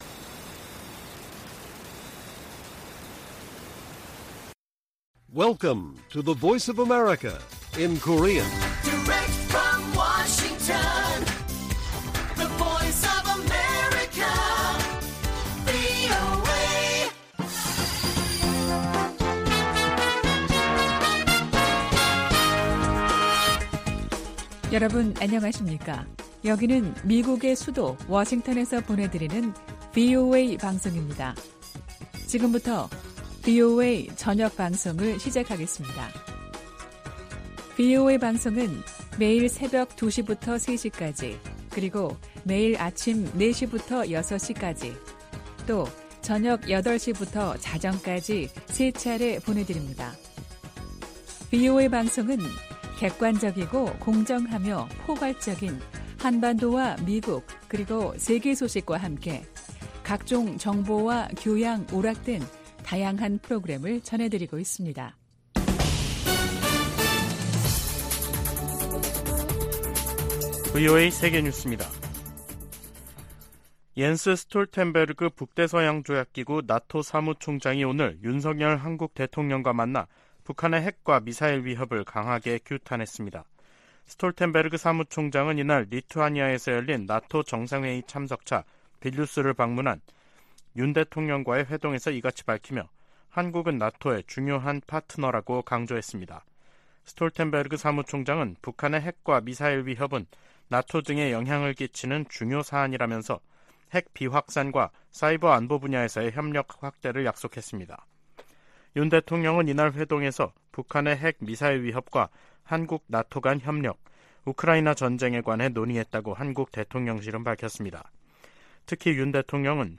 VOA 한국어 간판 뉴스 프로그램 '뉴스 투데이', 2023년 7월 11일 1부 방송입니다. 북한 김여정 노동당 부부장이 이틀 연속 미군 정찰기의 자국 상공 침범을 주장하며 군사적 대응을 시사하는 담화를 발표했습니다. 이와 관련해 미 국무부는 북한에 긴장 고조 행동 자제를 촉구했습니다. 미한 핵 협의그룹 NCG 첫 회의가 다음 주 서울에서 열립니다.